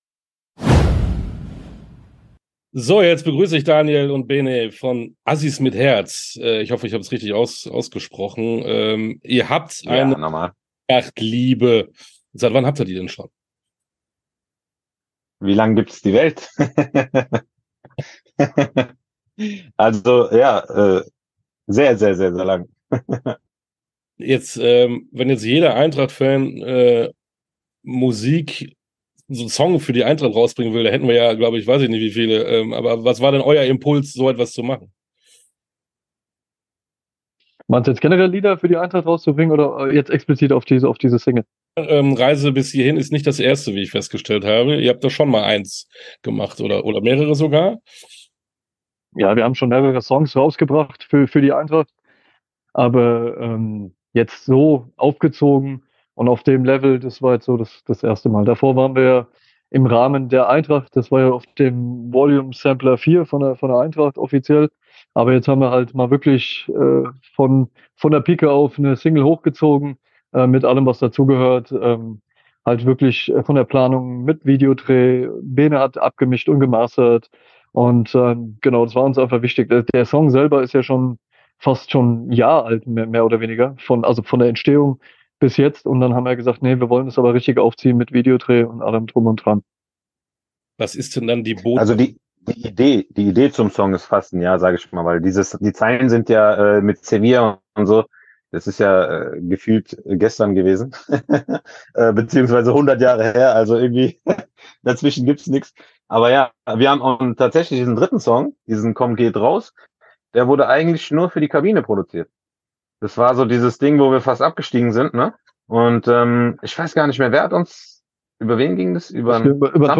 hier das Interview mit den Rappern Azzis mit Herz.
Interview_lang_-_Azzis_mit_Herz.mp3